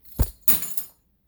MetalDrop
bink bonk clank clink crash drop falling-metal metal sound effect free sound royalty free Music